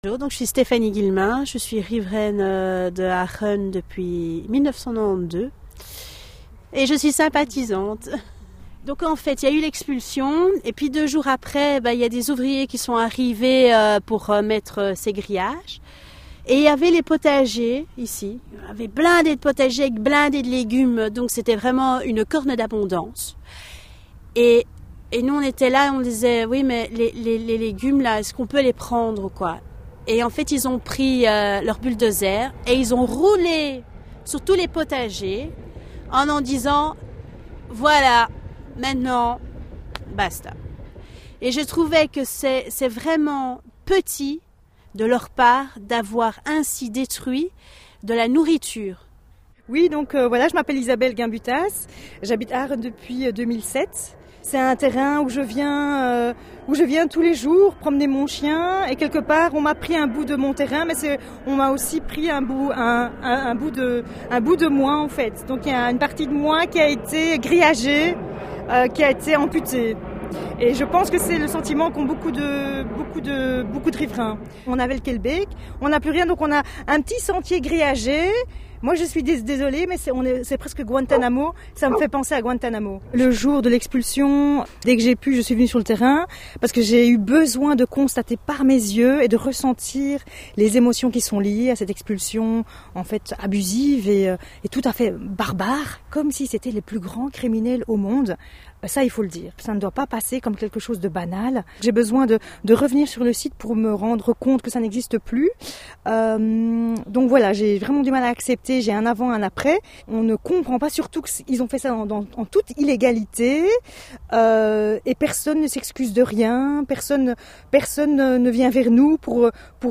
Interviews de 2015 et flashback sur la mobilisation d'avril 2014.